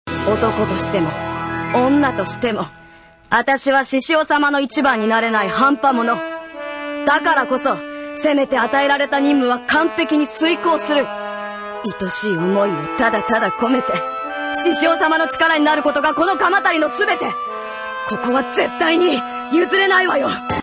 *Shrugs*  His voice was good, but nothing that really caught my attention.